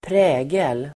Uttal: [²pr'ä:gel]